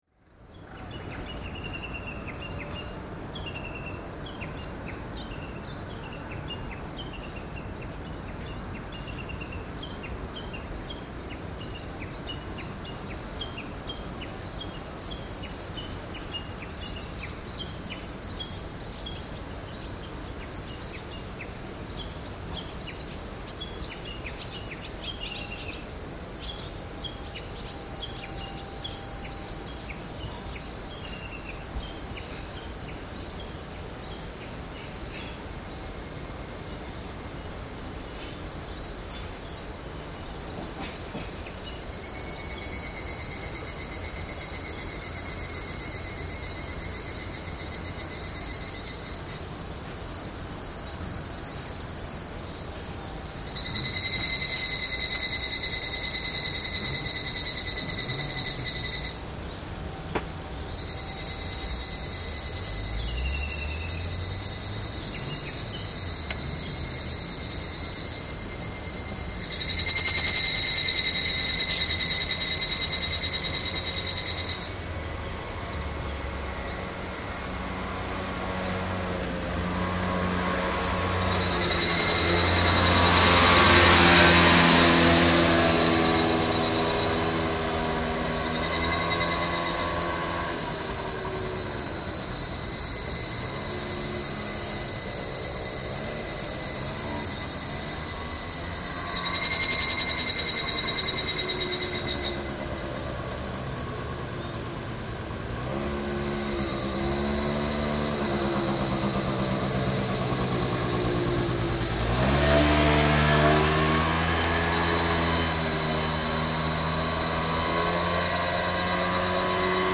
第１回は、亮月製作所（自宅２階）で聴く夕方の窓辺の音です。
なるべく質の良いヘッドフォンで窓に向かって聴いてみてください。
録音：カセットデンスケTC-D5M、マイクロフォンECM-MS957、TDKハイポジションカセットテープ、ドルビーB NR
夕方になると梅雨明けを待ち詫びていたさまざまな虫や鳥たちが好天の歓びを歌っていました。そして、ひぐらし。
ひぐらしの鳴き声が大好きです。夕方の生活の音も好きです。今日一日の暮らしをいたわるようなやさしさがあるように思います。